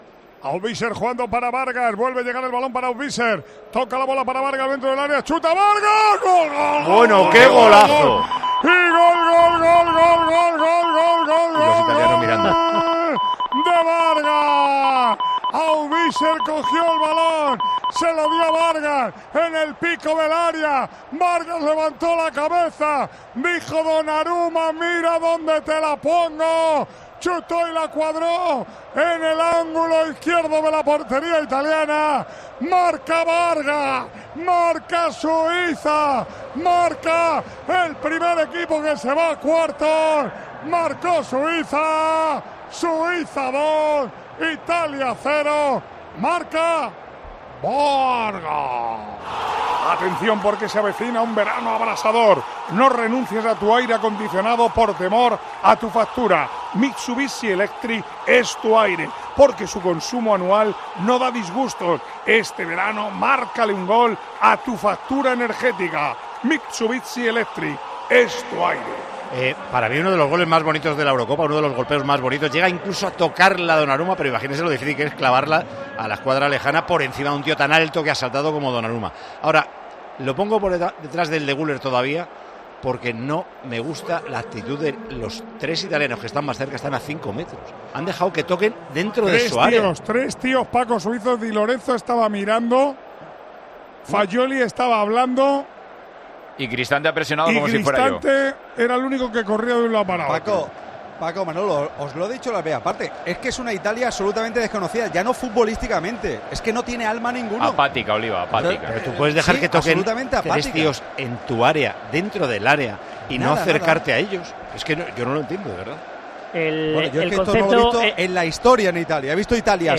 Al término del encuentro, el equipo de comentaristas de Tiempo de Juego fue muy crítico con el papel y la eliminación de Italia en esta Eurocopa.
Manolo Lama estuvo narrando el encuentro para Tiempo de Juego y sorprendió con la elección de los mejores jugadores italianos: "Roberto Boninsegna, Sandro Mazzola, Gianni Rivera, Luigi Riva, Angelo Domenghini".